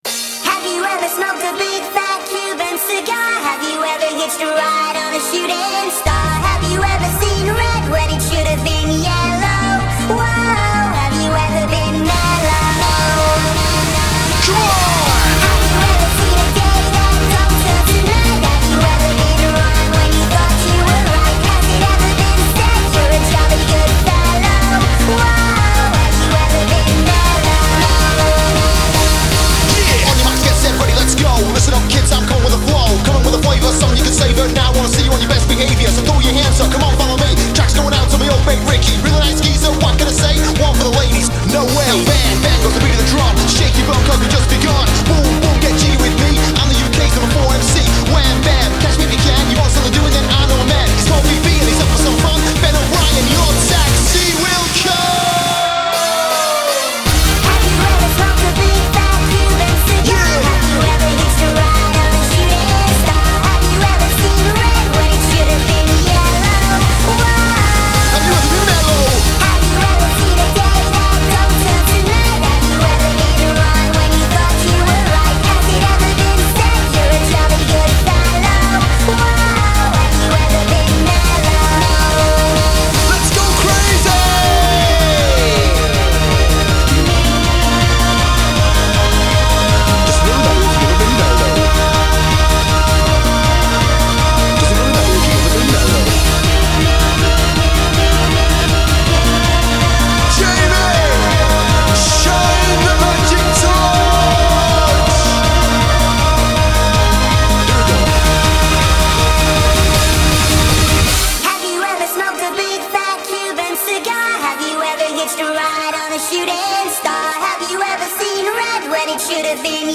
BPM160--1
Audio QualityPerfect (High Quality)